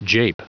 Prononciation du mot jape en anglais (fichier audio)
Prononciation du mot : jape